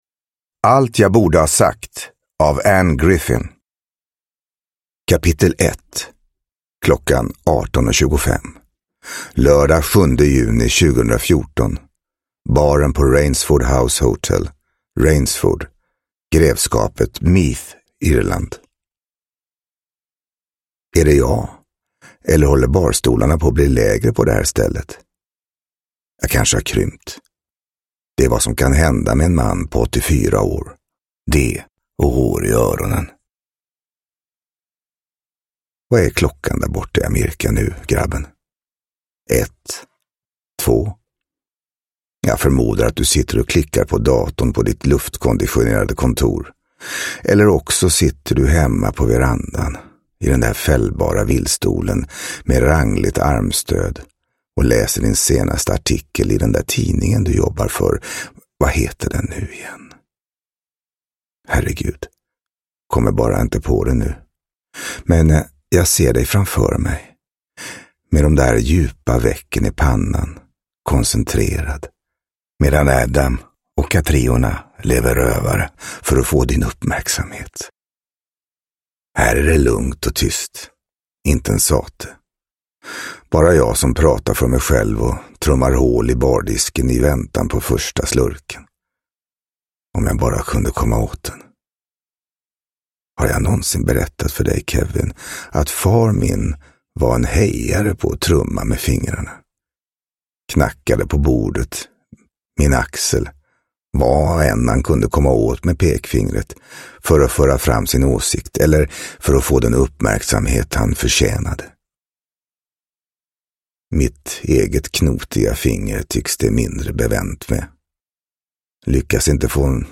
Allt jag borde ha sagt – Ljudbok – Laddas ner
Uppläsare: Johan Hedenberg